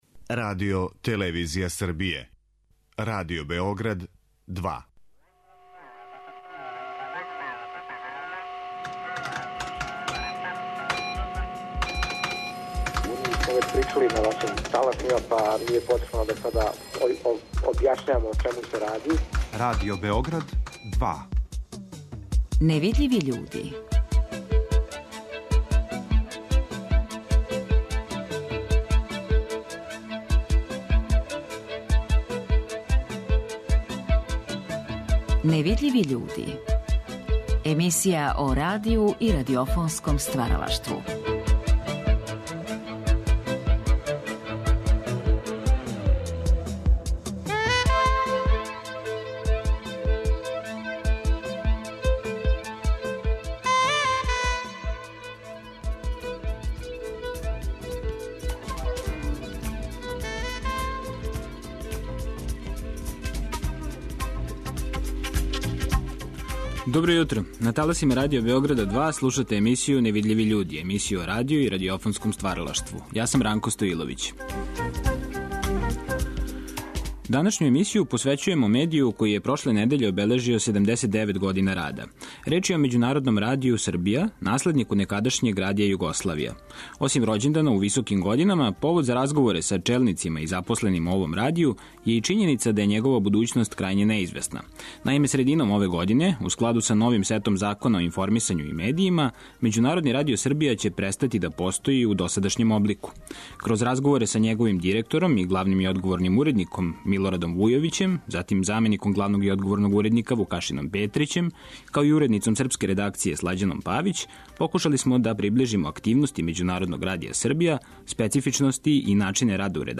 Ови разговори вођени су 1986. године у оквиру циклуса "Гост Другог програма".